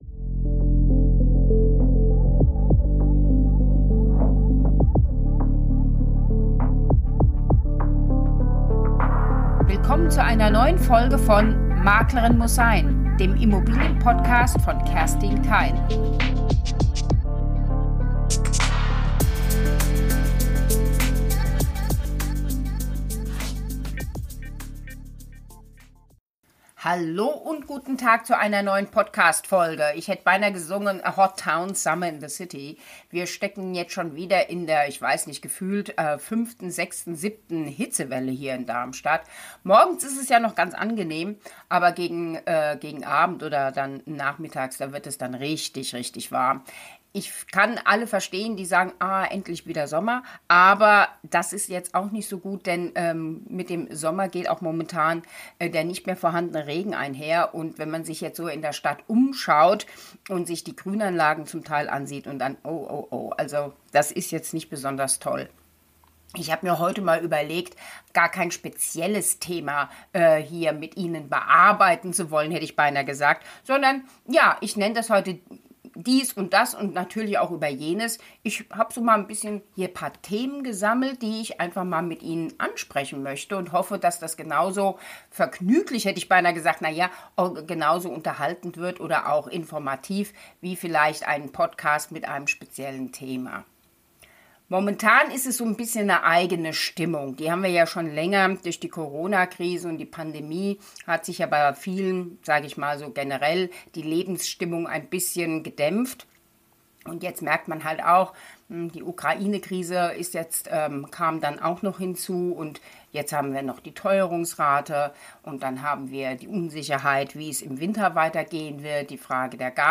in lockerem Plauderton